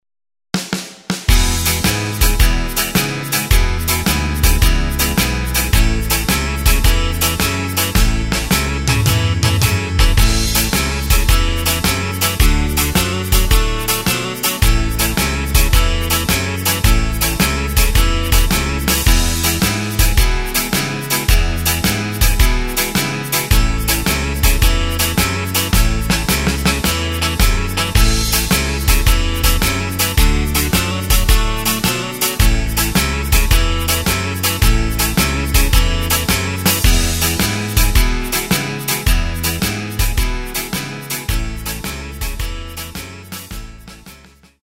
Takt:          4/4
Tempo:         108.00
Tonart:            A
Playback mp3 Demo